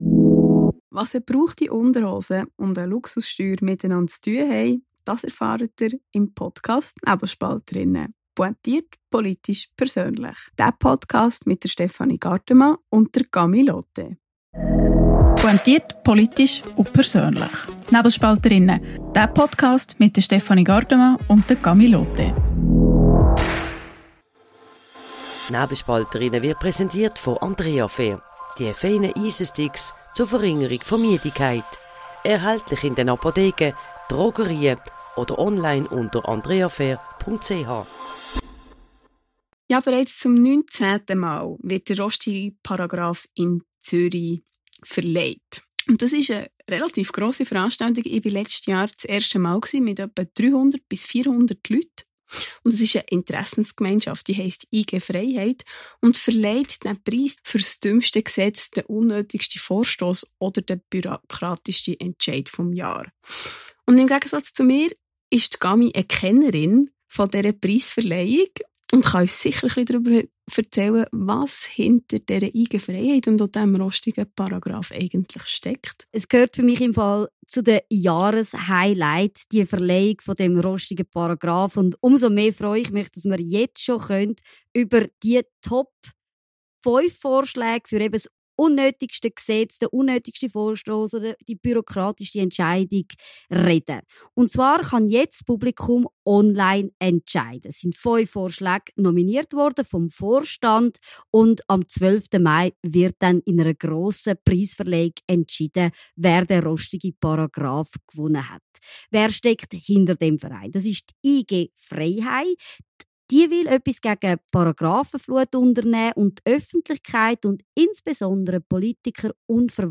Kurz vor ihrem Votum im Nationalrat trifft sie die beiden Nebelspalterinnen zum Interview.